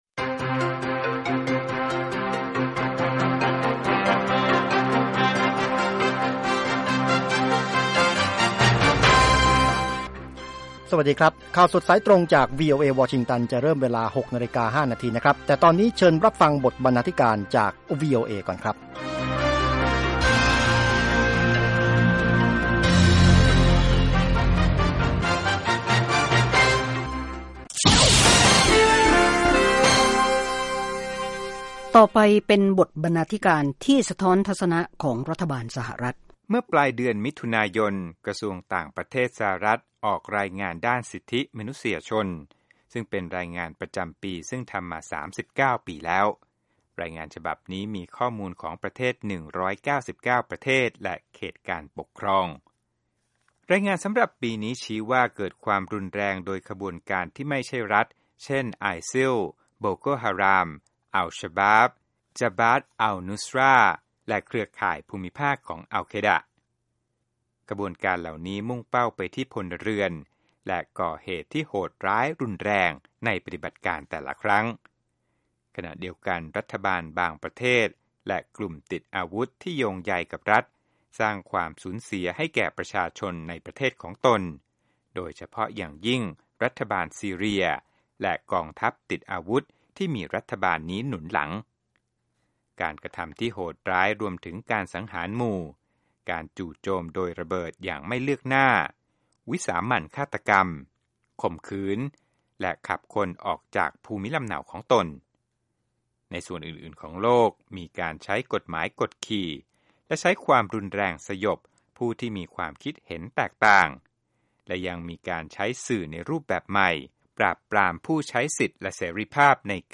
ข่าวสดสายตรงจากวีโอเอ ภาคภาษาไทย 6:00 – 6:30 น. อังคาร ที่ 14 กรกฎาคม 2558